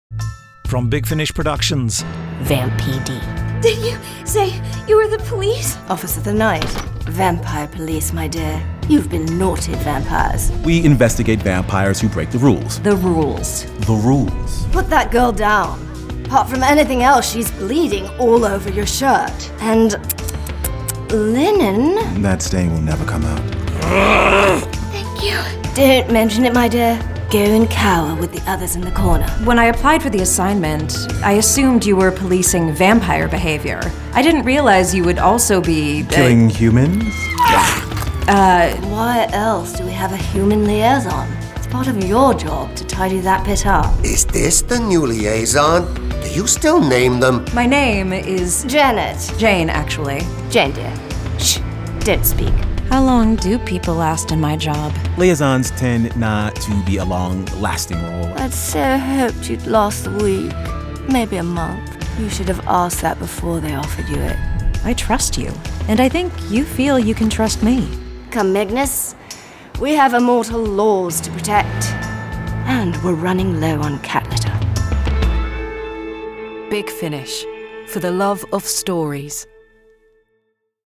Big Finish Originals 1. VAM PD Volume 01 Released October 2024 Written by James Goss Starring Juliet Landau Mara Wilson This release contains adult material and may not be suitable for younger listeners. From US $28.51 Download US $28.51 Buy Save money with a bundle Login to wishlist 20 Listeners recommend this Share Tweet Listen to the trailer Download the trailer